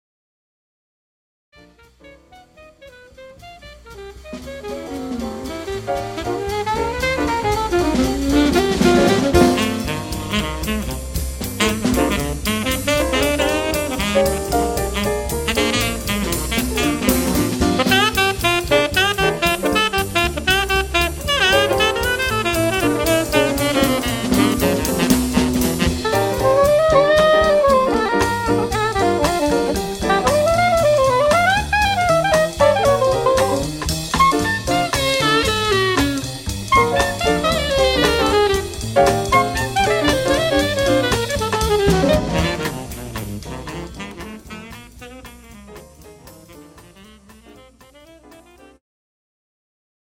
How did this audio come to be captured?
Recorded at The Sound Cafe, Midlothian